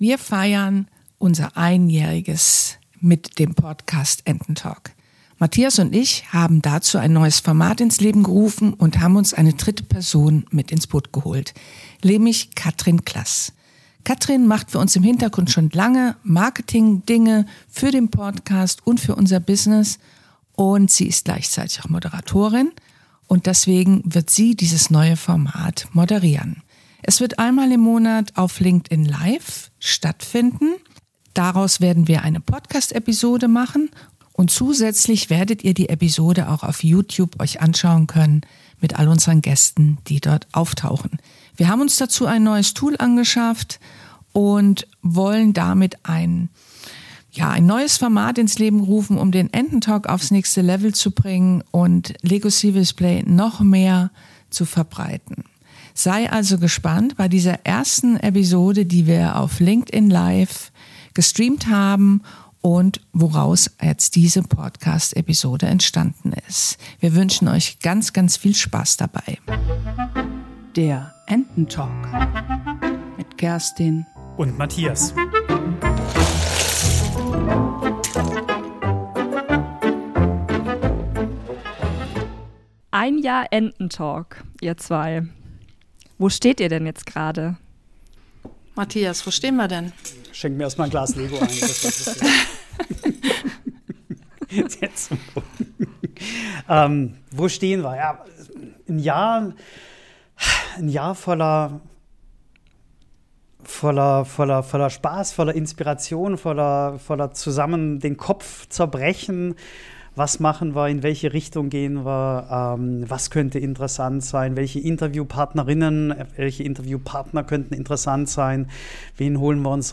In dieser speziellen Folge feiern wir das einjährige Bestehen unseres Podcasts Enten-Talk. Zu diesem besonderen Anlass haben wir mit einem neuen Format begonnen und unser erstes Live-Event auf LinkedIn gestreamt. Diese Aufnahme findest Du hier nun als Podcast-Folge.